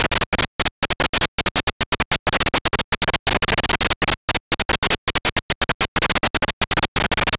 ANNCheering2.wav